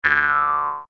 TL_step_on_rake.ogg